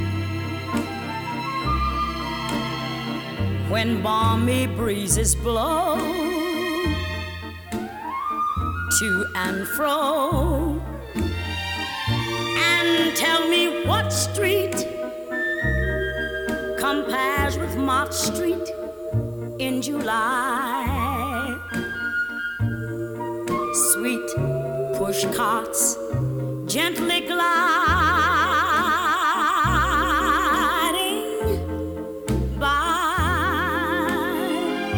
Жанр: Джаз